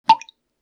drip.wav